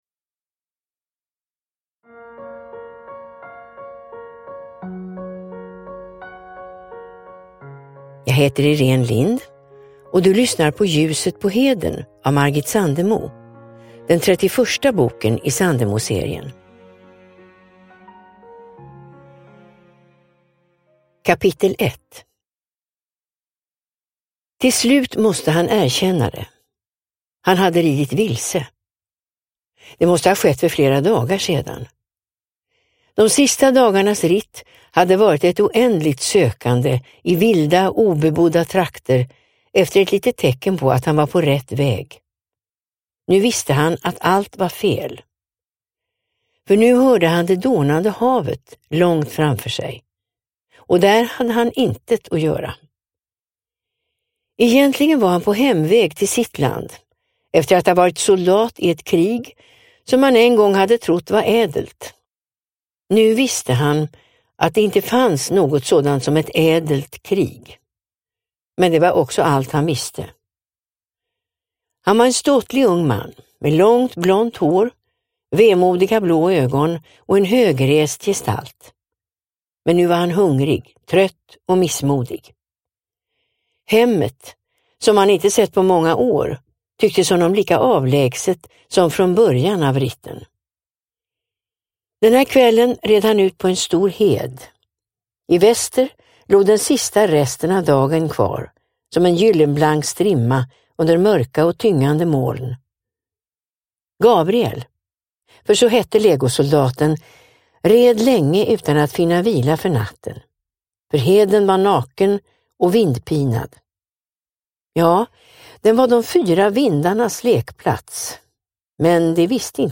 Ljuset på heden – Ljudbok – Laddas ner
Sandemoserien är en unik samling fristående romaner av Margit Sandemo, inlästa av några av våra starkaste kvinnliga röster.